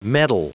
Prononciation du mot medal en anglais (fichier audio)
Prononciation du mot : medal